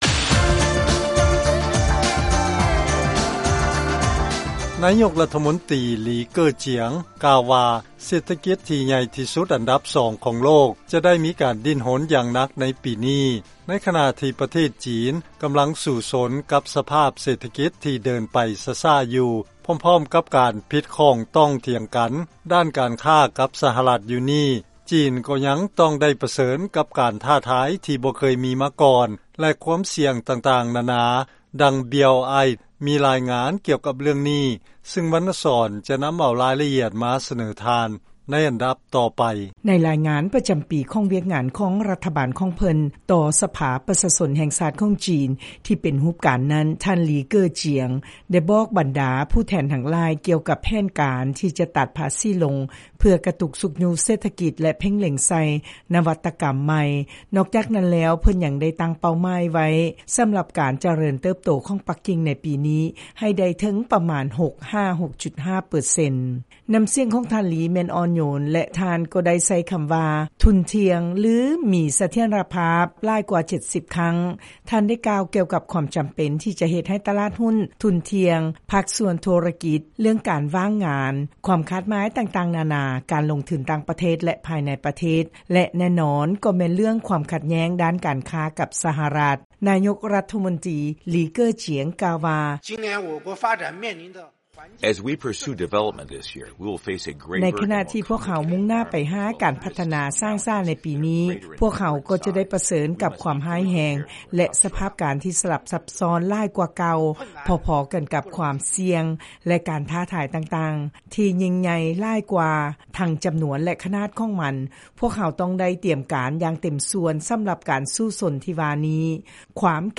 ລາຍງານກ່ຽວກັບການທ້າທາຍແລະການອ່ອນຕົວຂອງເສດຖະກິດຈີນ